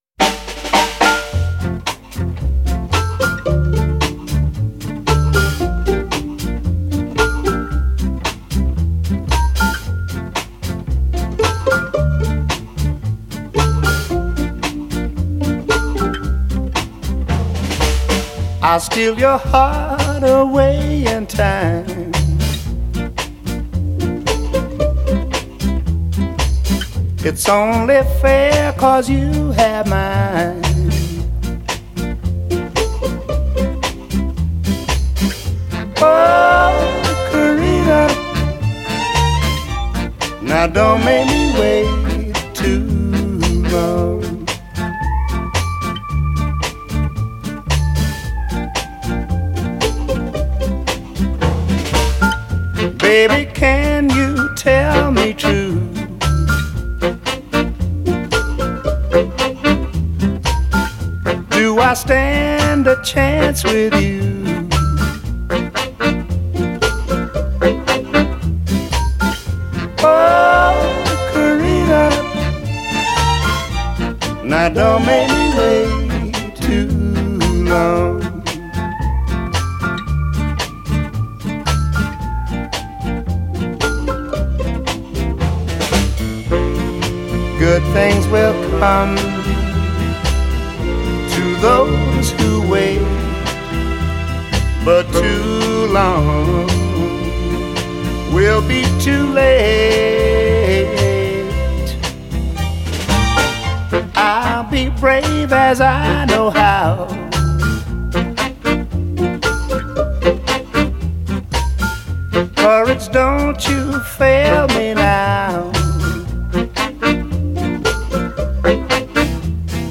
British retro-soul revival